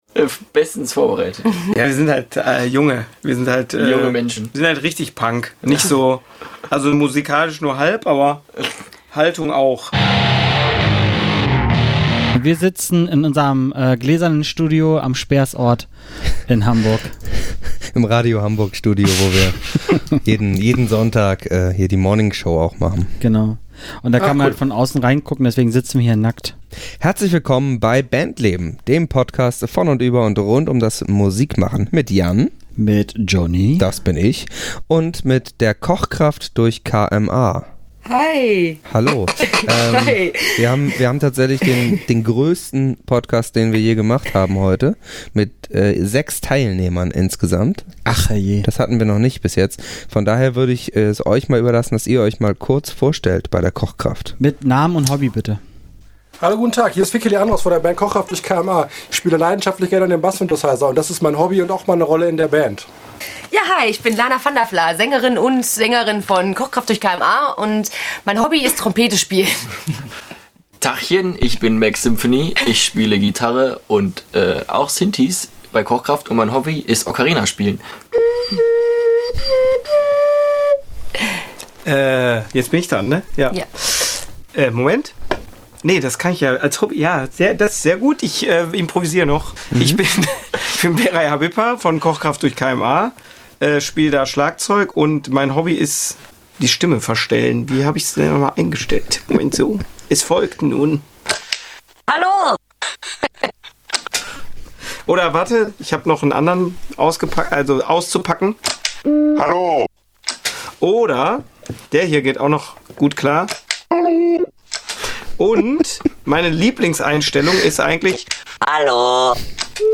Letzte Episode Kochkraft durch KMA 12. Februar 2020 Nächste Episode download Beschreibung Teilen Abonnieren Neo-Dada irgendwo zwischen Kindergarten, Death Metal und Post-Punk, oder doch Indie? Wir haben versucht uns mit der Kochkraft durch KMA über ihren Werdegang, ihre Kunst und vieles Anderes zu unterhalten.